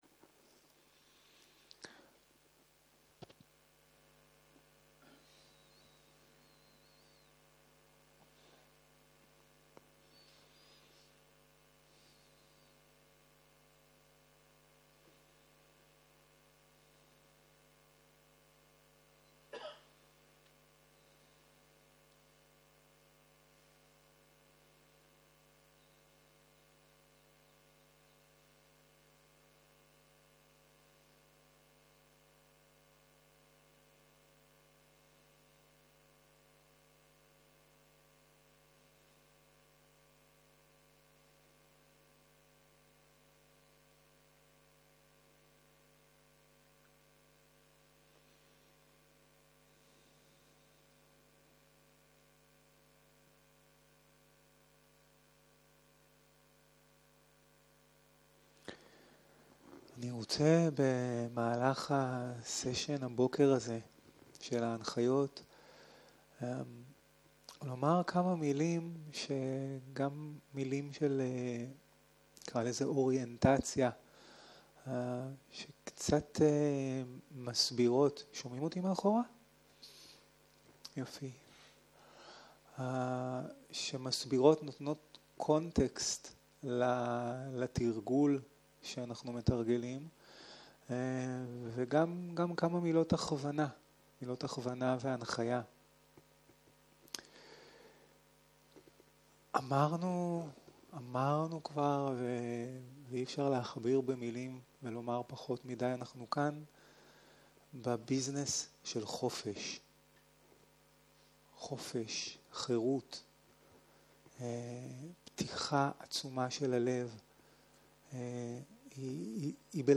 02.02.2023 - יום 2 - בוקר - הנחיות מדיטציה - תנוחת גוף ותנוחת ישיבה, איסוף התודעה - הקלטה 2
Dharma type: Guided meditation